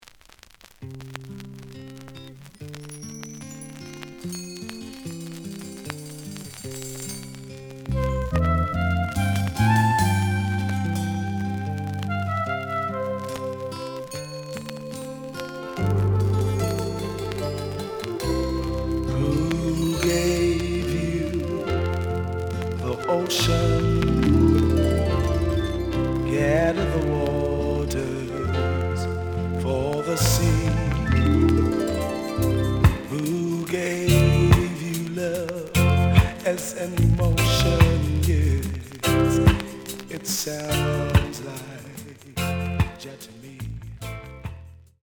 The audio sample is recorded from the actual item.
●Format: 7 inch
●Genre: Reggae